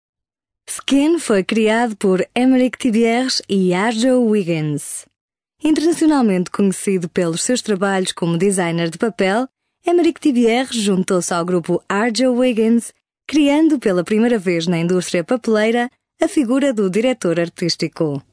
Portuguese flexible female voice with different registers, from a woman, young mother's to a child's. Voice over; Narration; Institutional videos; Documentary; Publicity.
Sprechprobe: eLearning (Muttersprache):
Professional Portuguese VO talent.